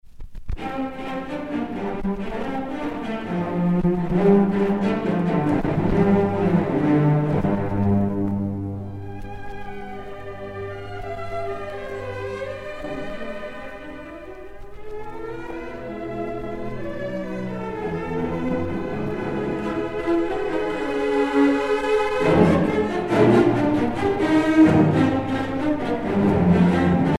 Ensemble de cent deux violoncelles
danse : sardane